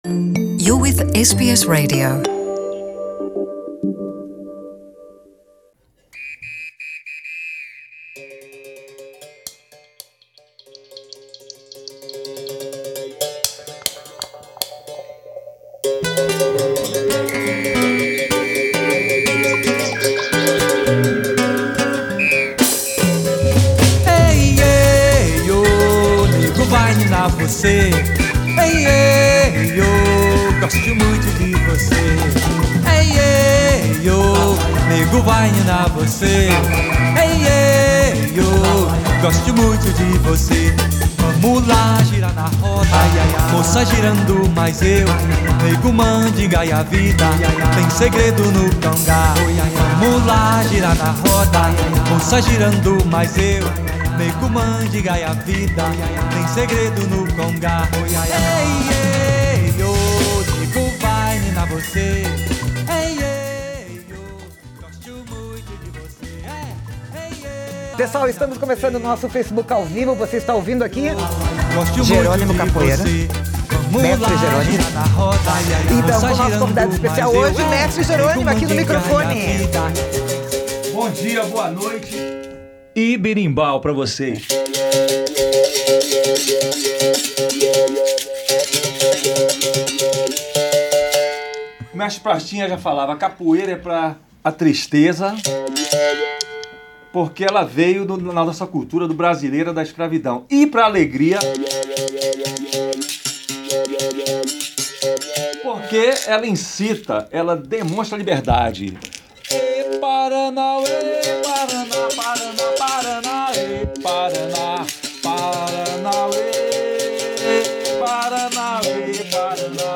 Nessa entrevista